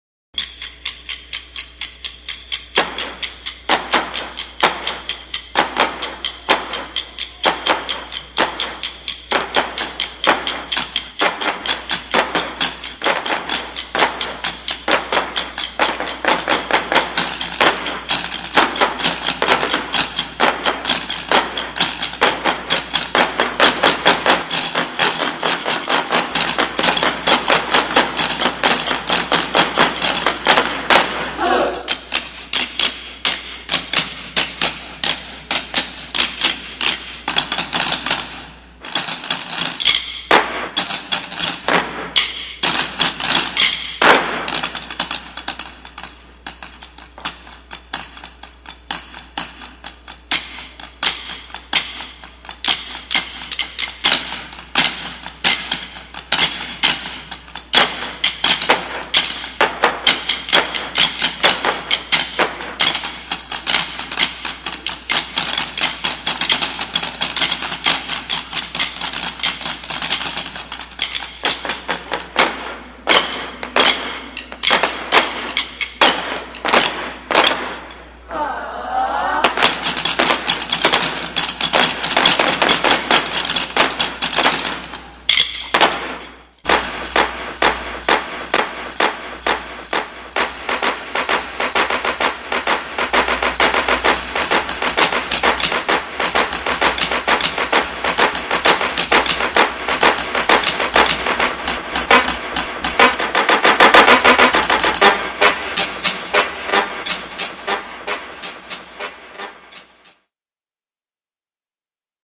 Voicing: Percussion [10 to 12 players]